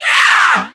mech_mike_die_vo_04.ogg